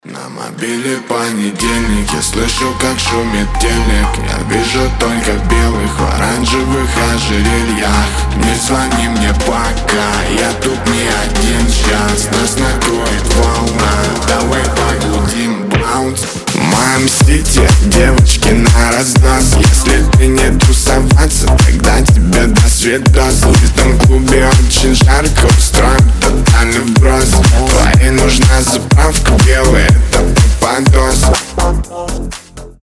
громкие
house